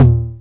VOL-1 & 2/VOL-2/SINGLE HITS